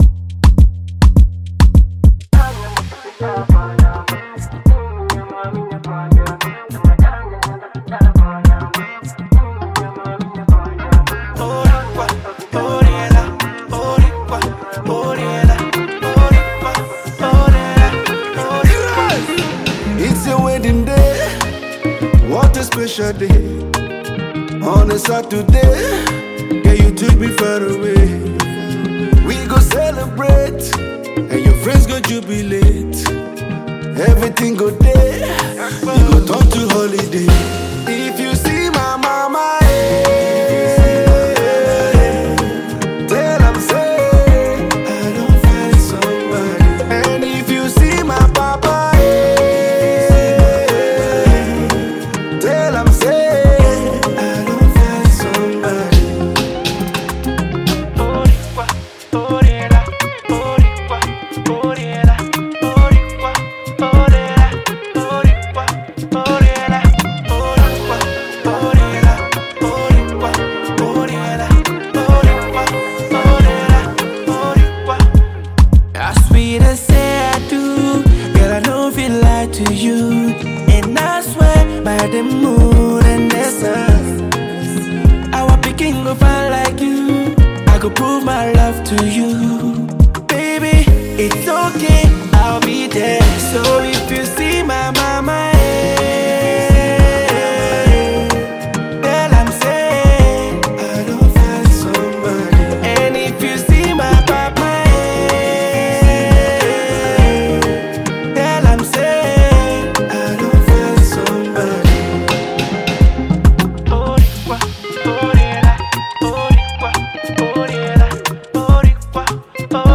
Nigerian veteran duo